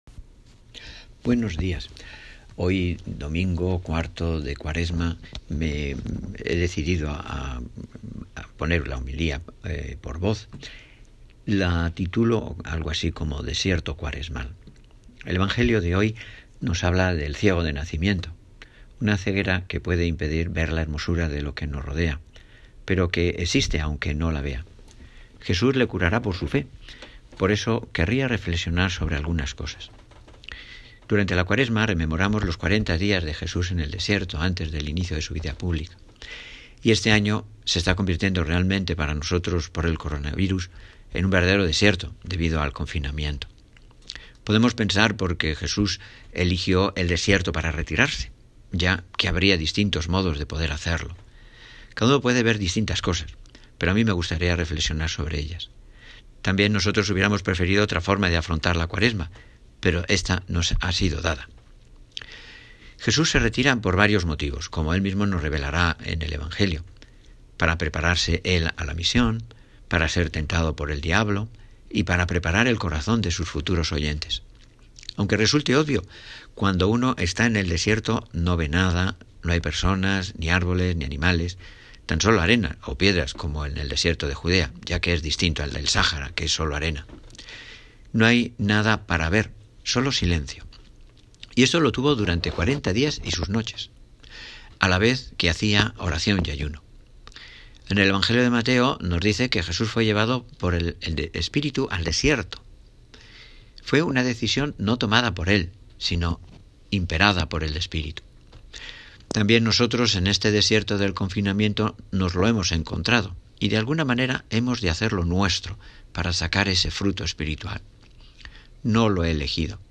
Hoy compartimos con vosotros la homilía de nuestro Párroco sobre el Evangelio de hoy, cuarto domingo de Cuaresma.